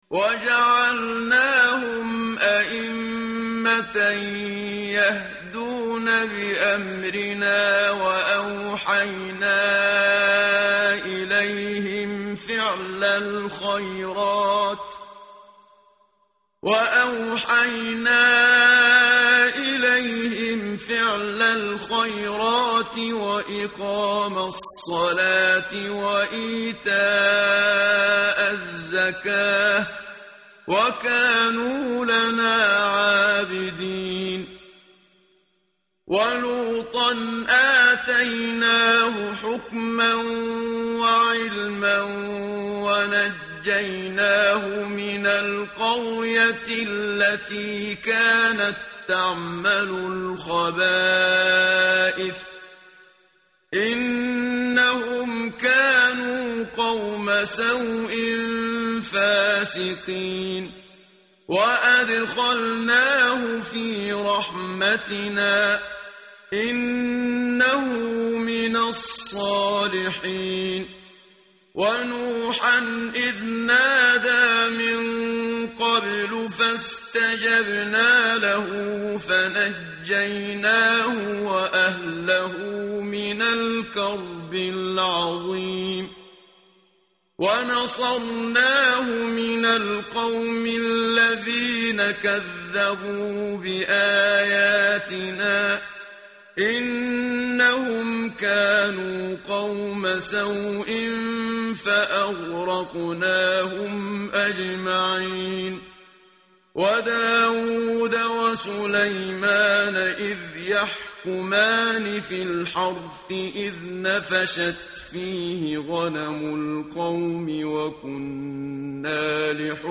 tartil_menshavi_page_328.mp3